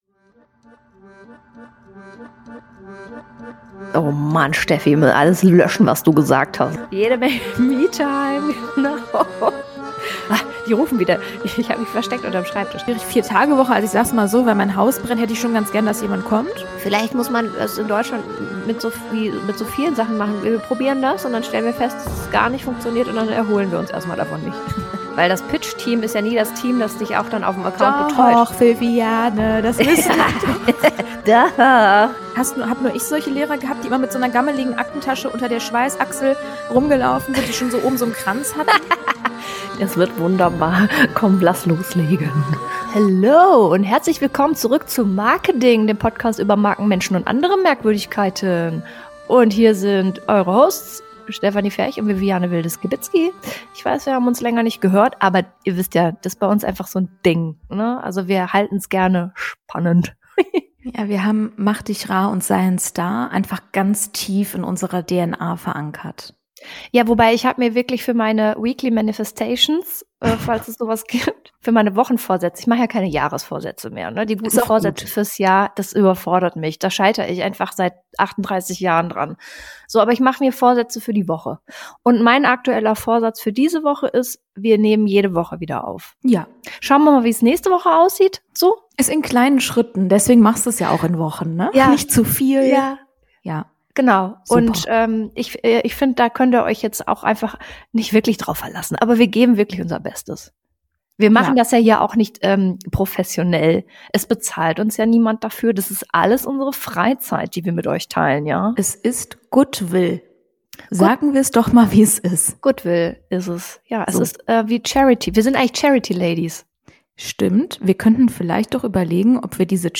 Herzlich Willkommen zu dem Podcast von zwei Beraterinnen, die elfengleich von Fettnäpfchen zu Fettnäpfchen hüpfen.